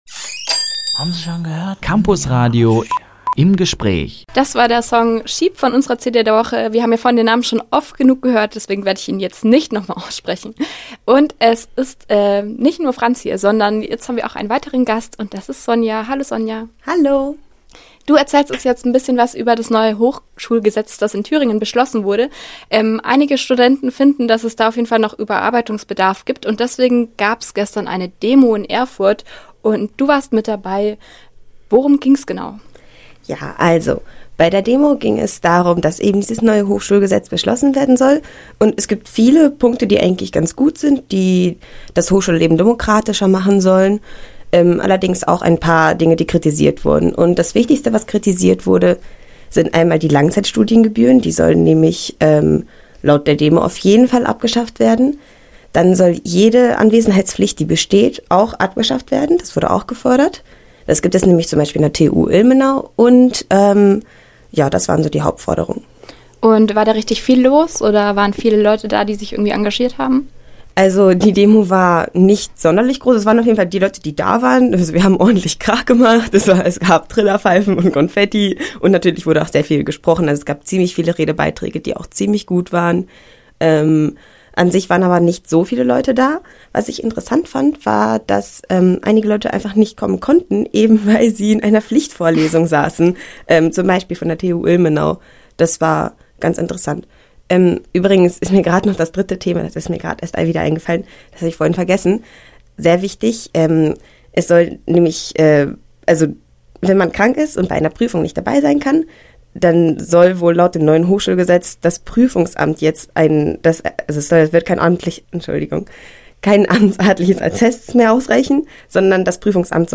Im Gespräch: “Studis fight the power!”